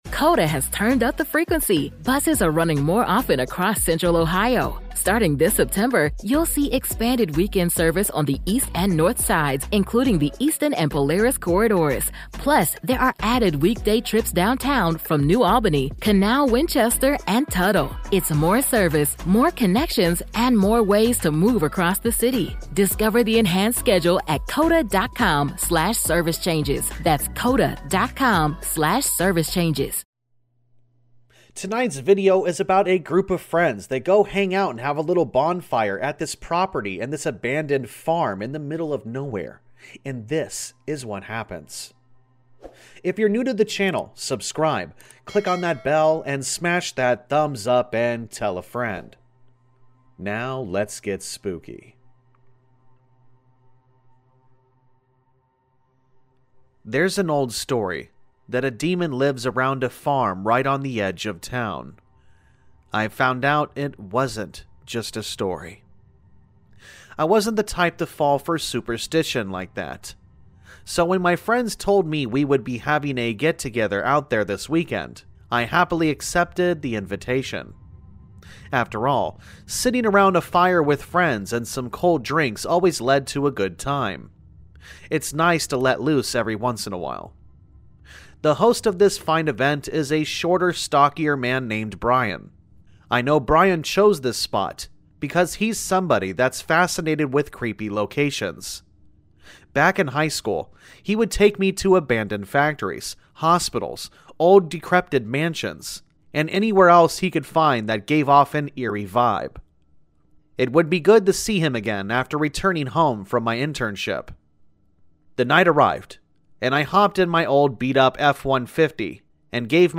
Sound Effects Credits
All Stories are read with full permission from the authors